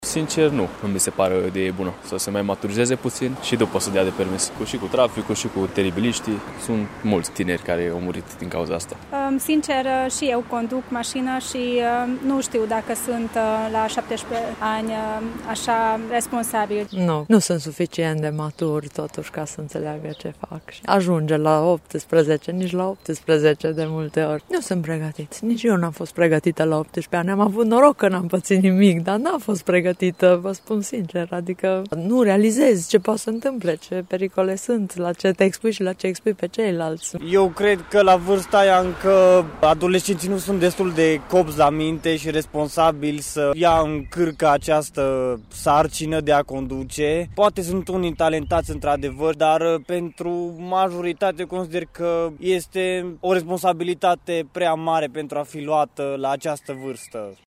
Nu la fel cred părinții sau tinerii cu vârsta de peste 20 de ani care consideră că nu există suficientă maturitate la 17 ani pentru a conduce, mai ales în traficul din România: